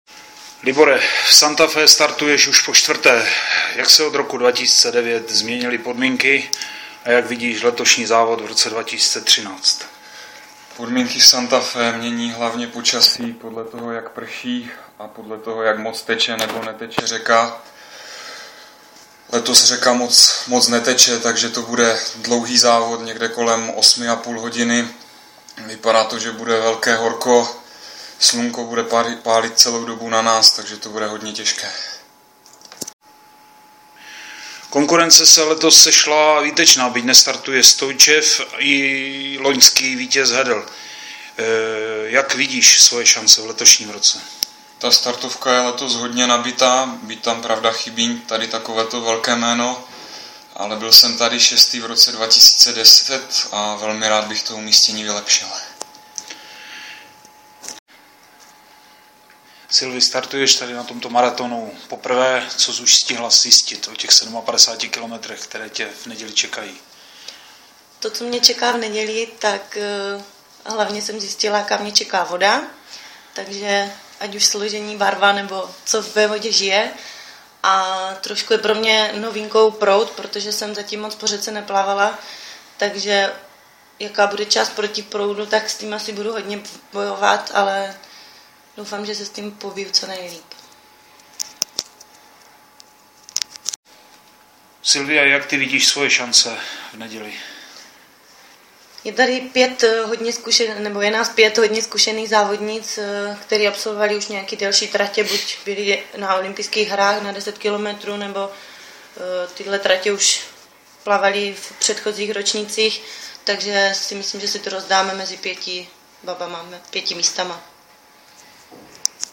Na konci článku naleznete krátký audio rozhovor s našimi plavci přímo z Argentiny.